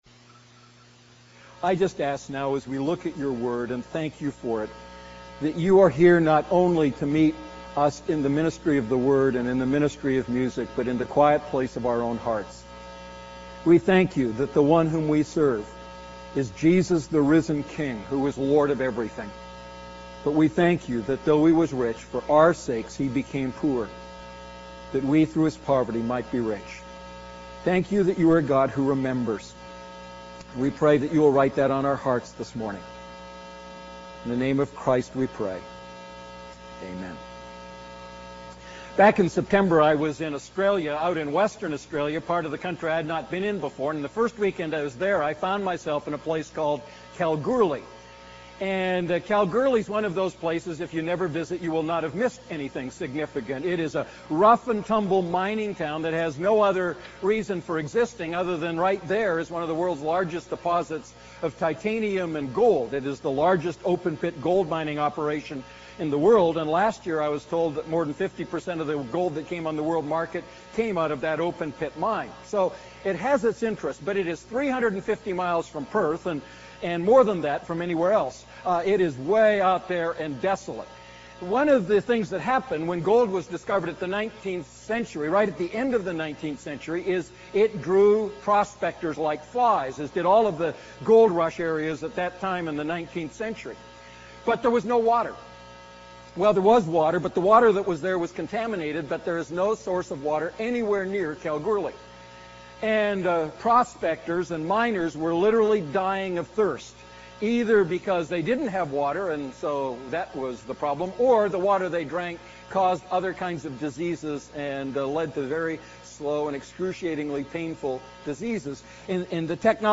A message from the series "Prayer."